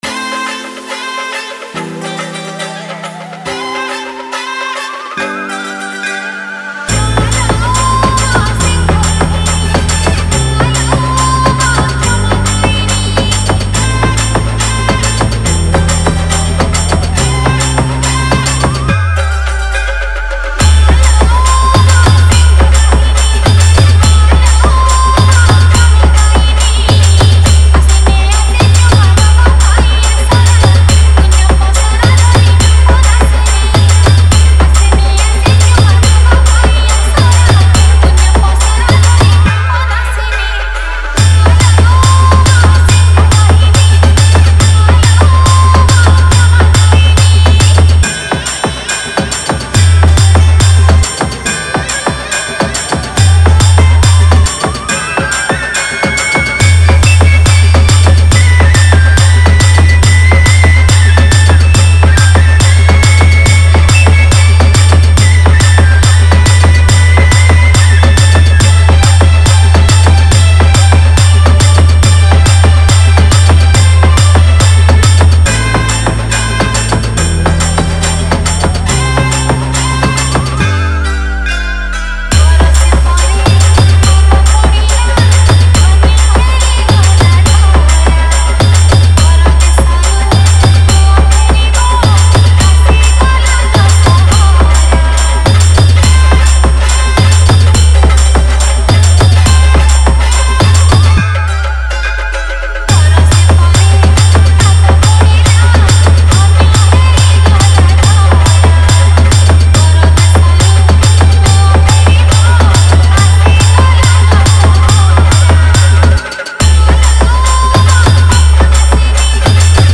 Durga Puja Special Dj Song Songs Download